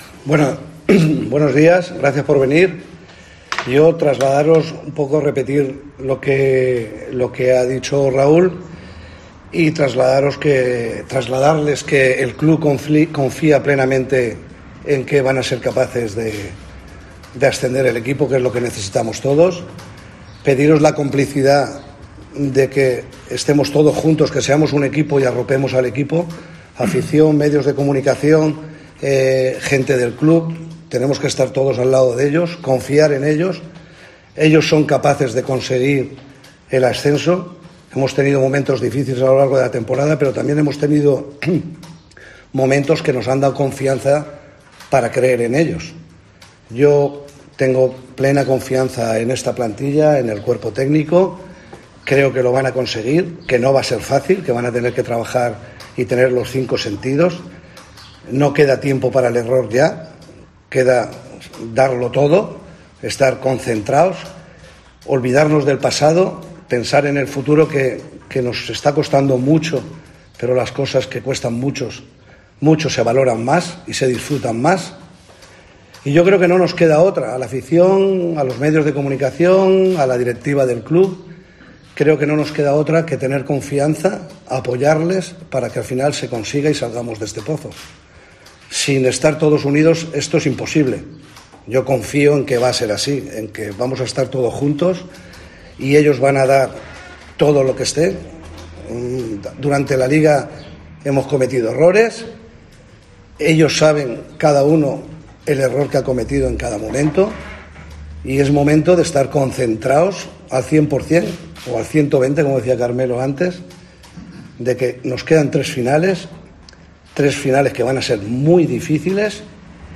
Dirigentes, técnicos y jugadores ofrecen una rueda de prensa en el Rico Pérez.